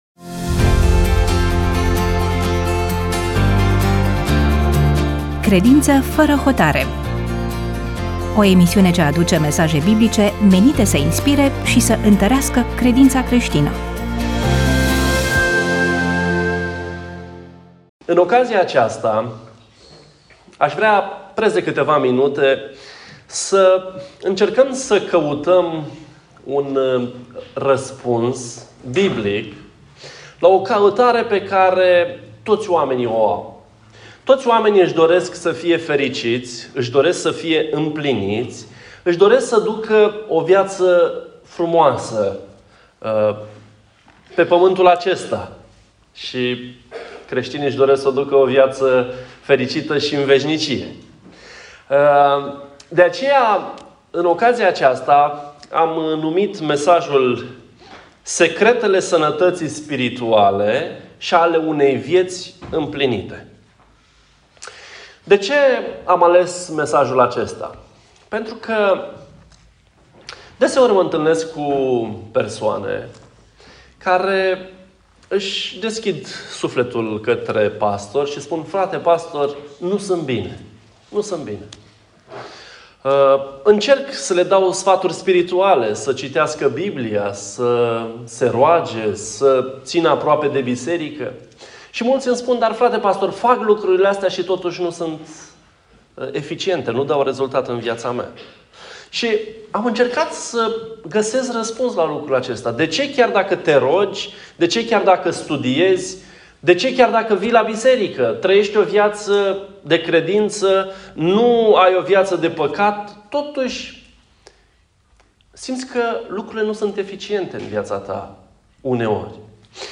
EMISIUNEA: Predică DATA INREGISTRARII: 03.08.2025 VIZUALIZARI: 69